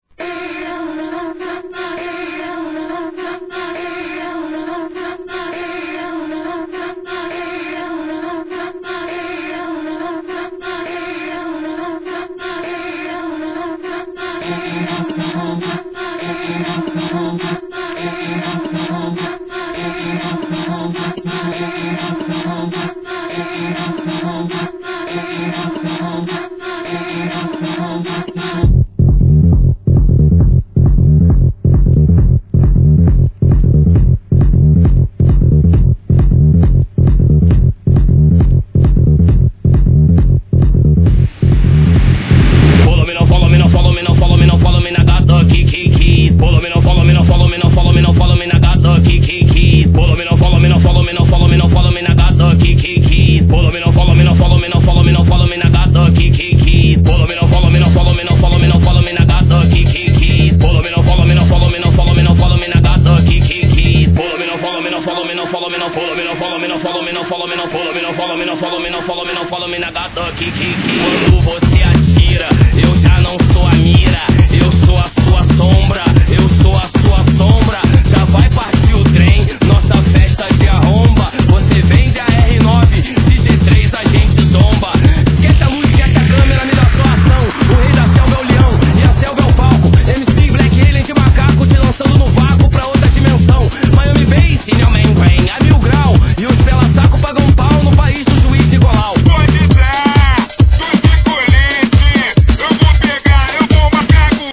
HIP HOP/R&B
ラガSTYLEのイケイケMCを乗せたトライバル・サンバでREMIX!!